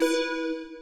line-clear.ogg